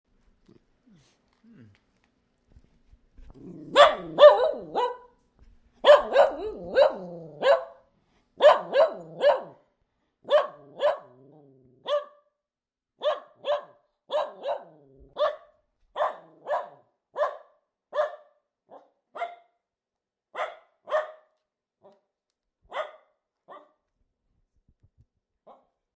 037068_miniature Dachshund Bark Botão de Som